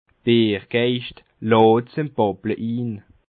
Bas Rhin
Ville Prononciation 67
Schiltigheim